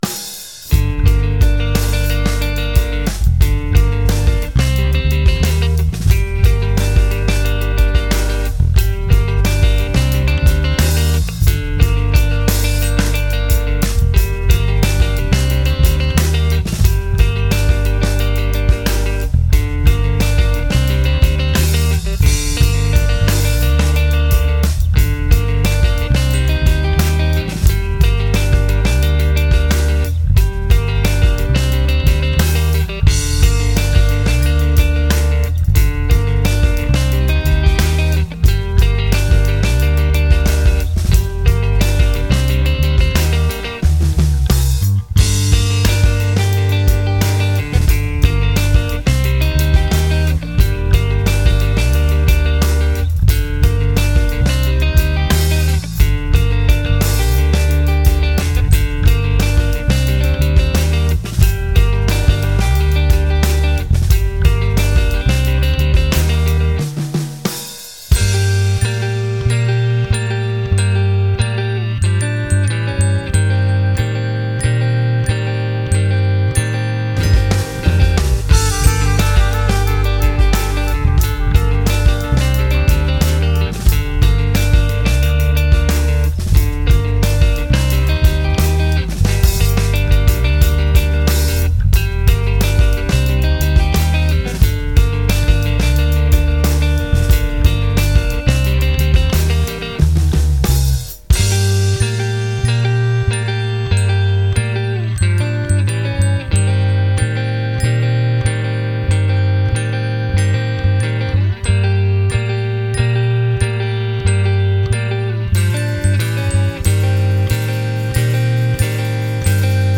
Rock, pop
Musique enregistrée